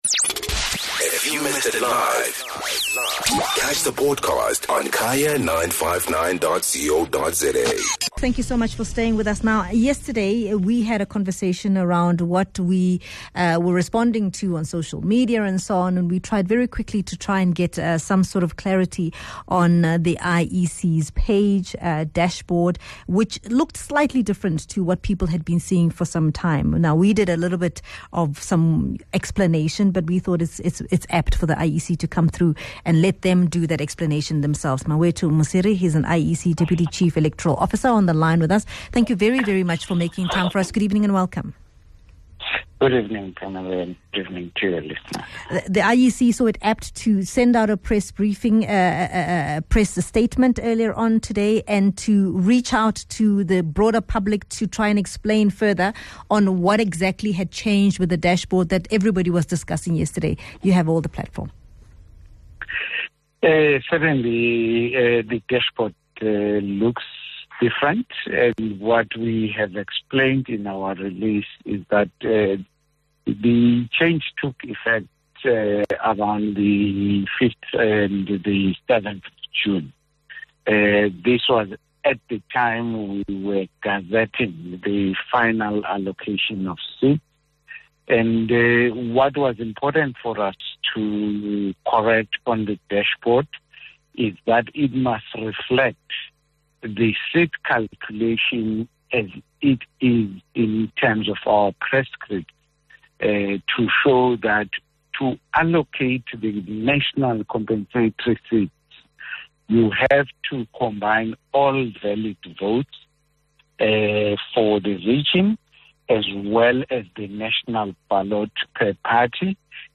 Guest: Mawethu Mosery - IEC Deputy Chief Electoral Officer